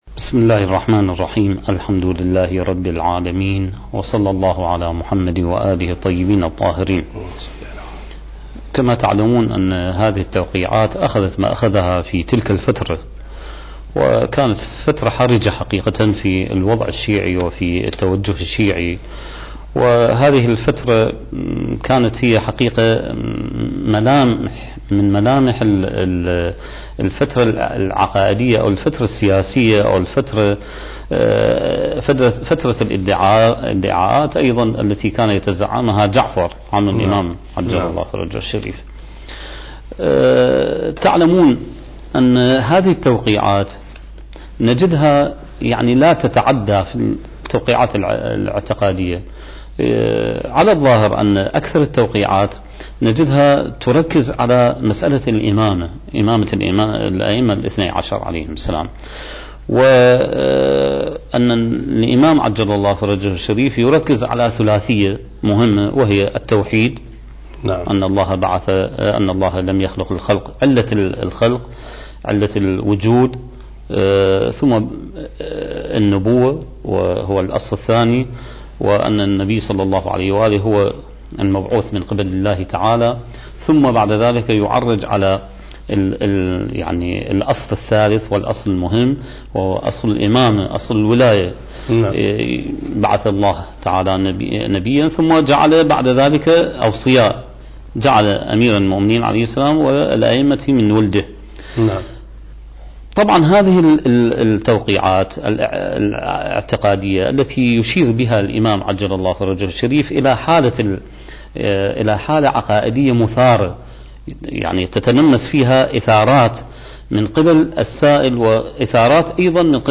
سلسلة محاضرات: بداية الغيبة الصغرى (6) برنامج المهدي وعد الله انتاج: قناة كربلاء الفضائية